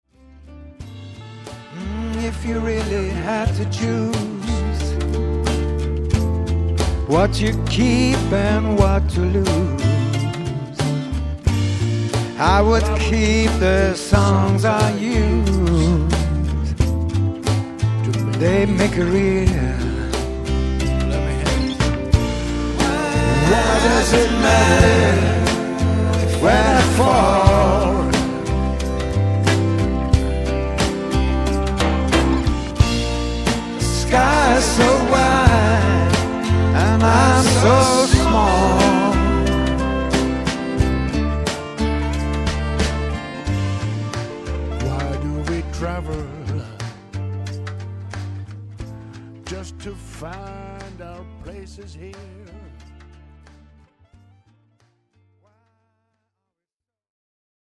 and recorded at home